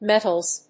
/ˈmɛtʌlz(米国英語), ˈmetʌlz(英国英語)/